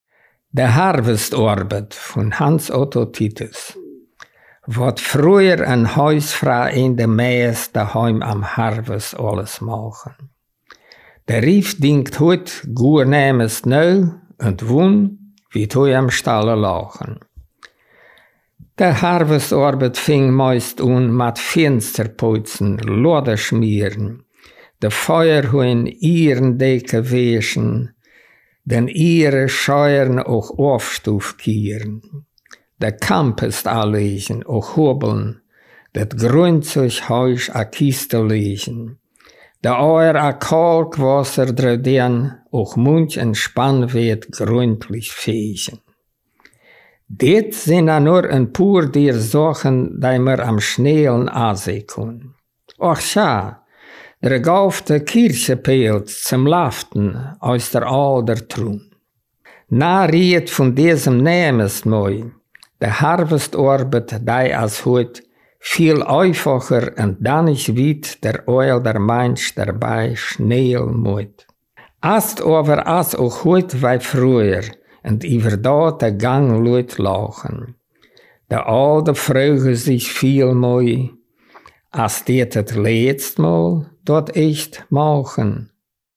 Ortsmundart: Heldsdorf